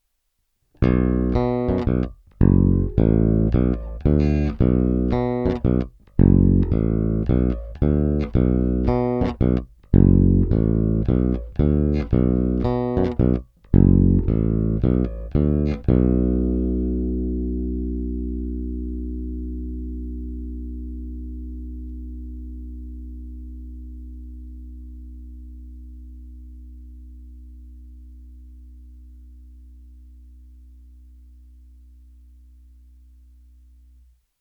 I přestože jsou na base půl roku staré struny, což u Elixirů v zásadě nic neznamená, je slyšet, že nové snímače mají o něco mohutnější basy a brilantnější výšky, než ty původní mexické.
Snímač u kobylky – původní